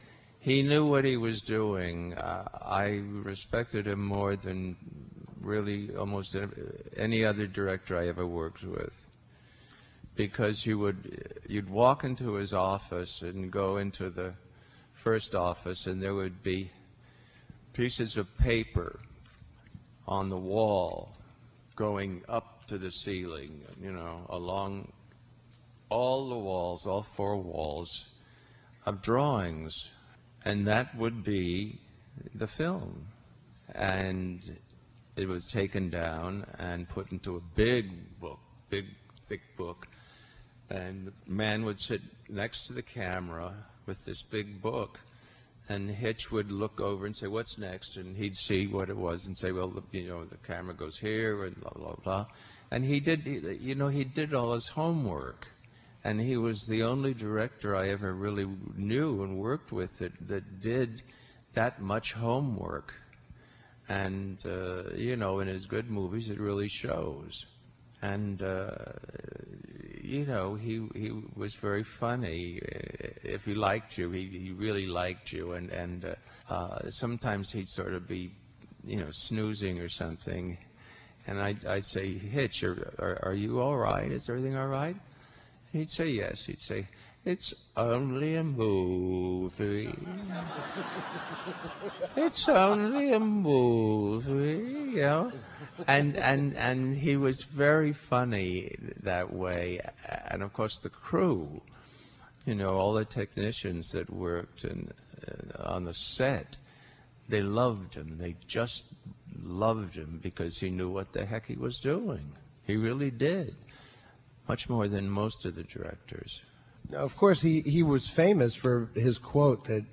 Farley Granger interviewed by Eddie Muller AUDIO STREAM
On stage with Eddie Muller at the opening night of the January 2006 NOIR CITY, following a sold-out showing of Strangers on a Train, Farley Granger talks about working with Hitchcock in this excerpt from the interview.